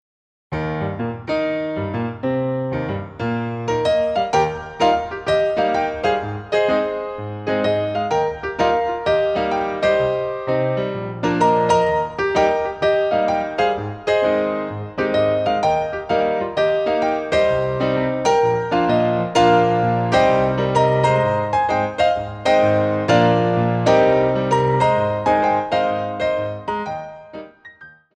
TENDU IN THE CENTRE II